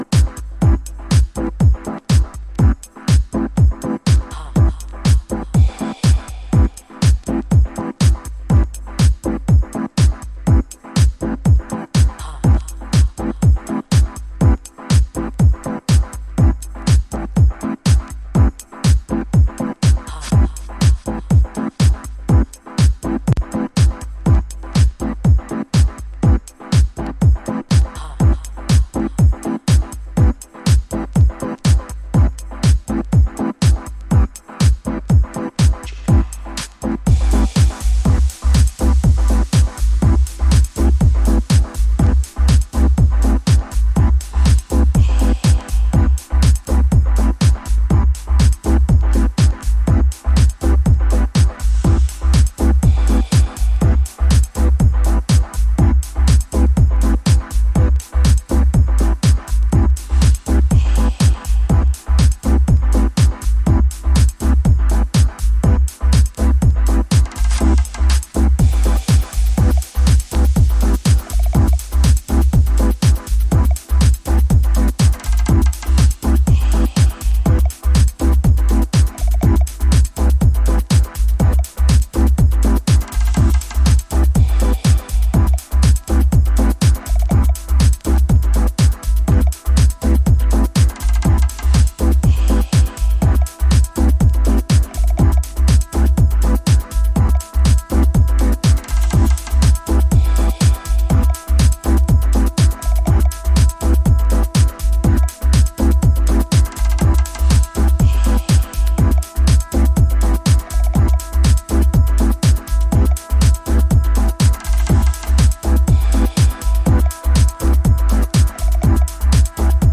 ダビーなシンセを軸に抜き差し展開していくAサイド、ボイスサンプルとピアノフレーズが印象的なB1、スローモーハウスB2。
House / Techno